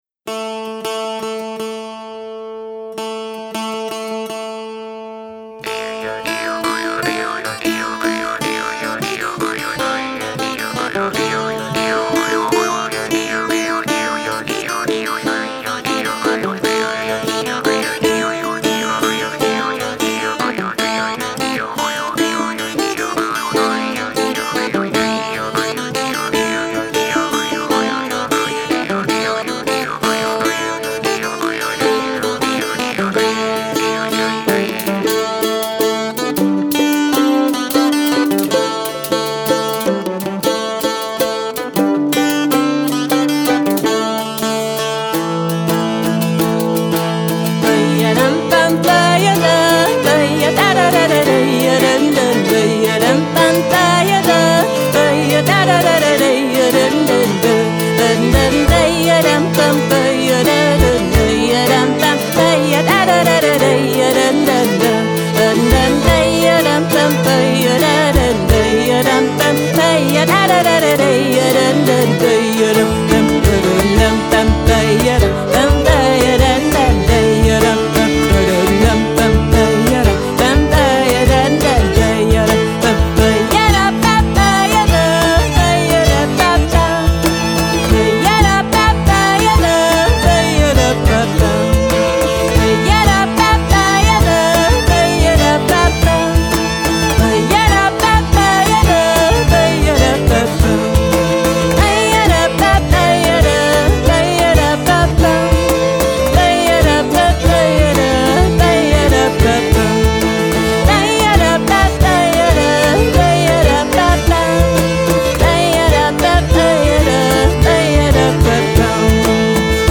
Folk & More